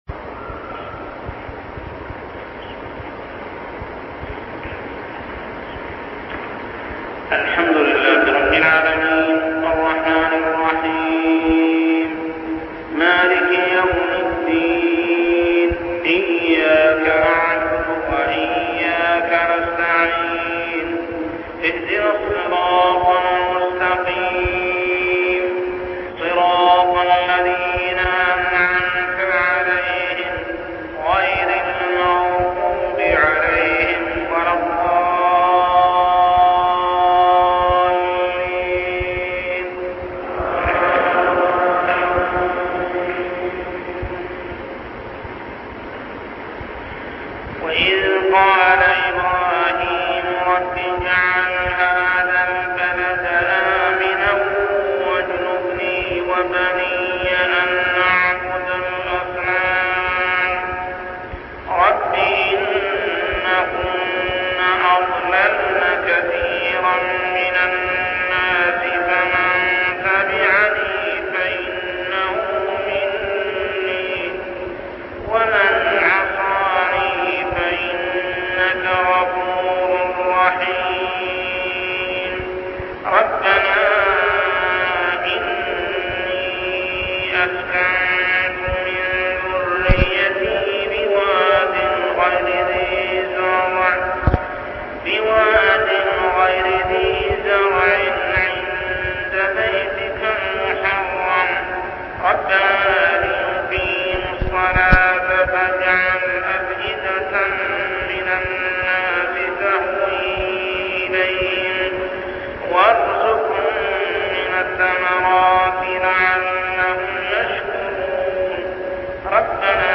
تلاوة من صلاة الفجر لخواتيم سورة إبراهيم 35-52 عام 1399هـ | Fajr prayer Surah Ibrahim > 1399 🕋 > الفروض - تلاوات الحرمين